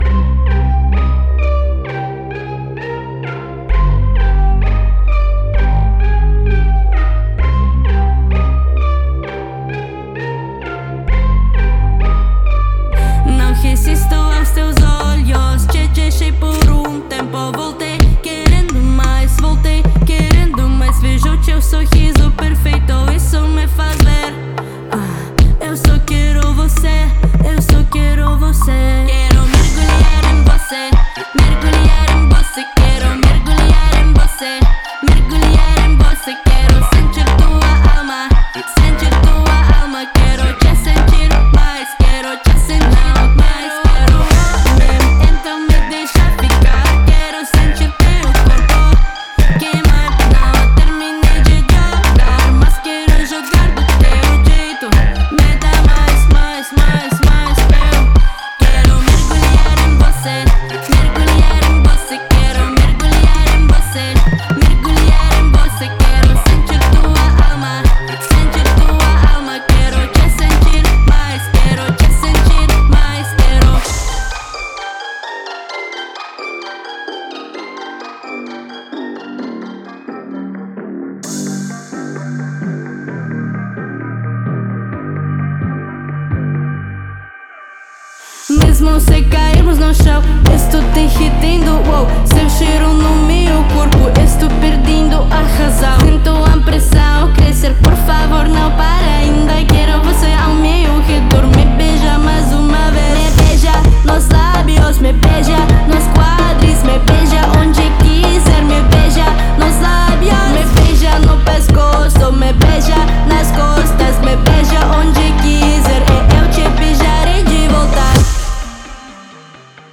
Genre:Trap
・表現力豊かなアドリブ、チャント、フック──コーラスのドロップやタフなバースに最適
・ストリートのフリースタイルセッションのように響くドラムループとワンショット
100 - 140 BPM